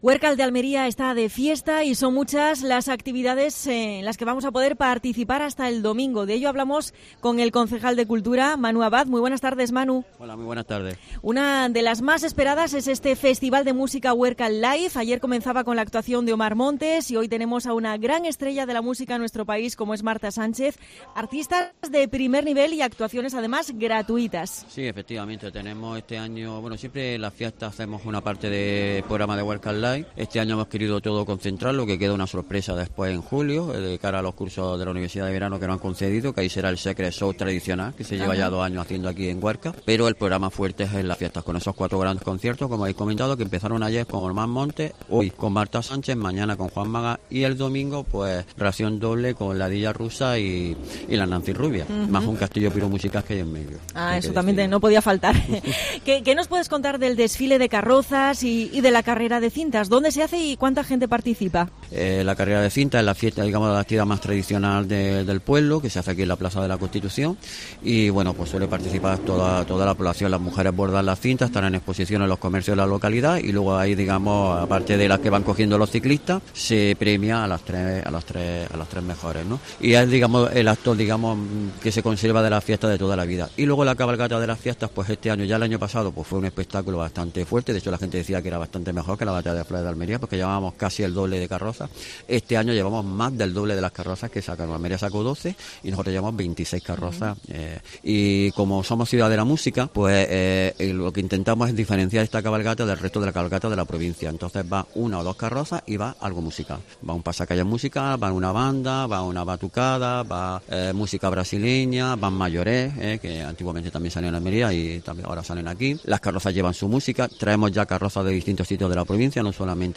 Entrevista a Manuel Abad (concejal de Cultura de Huércal de Almería)